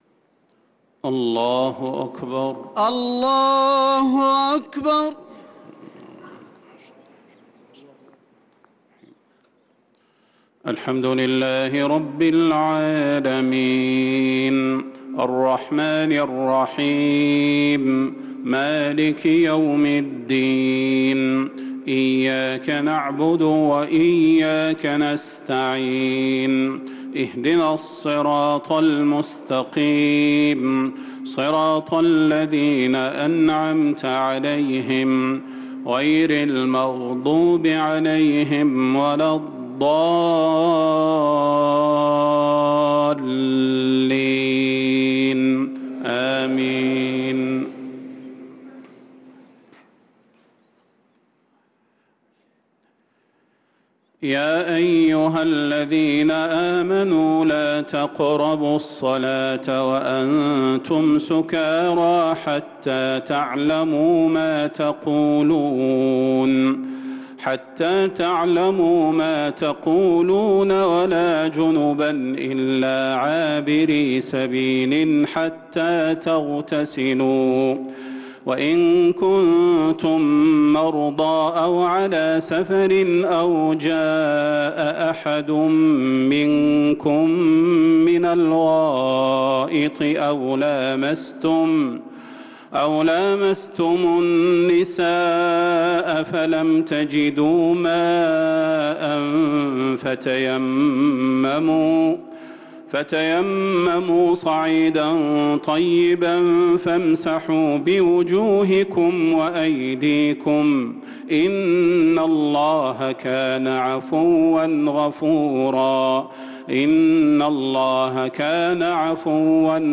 صلاة التراويح ليلة 6 رمضان 1445 للقارئ فيصل غزاوي - التسليمتان الأخيرتان صلاة التراويح
تِلَاوَات الْحَرَمَيْن .